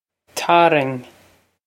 Pronunciation for how to say
Tare-ing
This is an approximate phonetic pronunciation of the phrase.